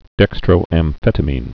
(dĕkstrō-ăm-fĕtə-mēn, -mĭn)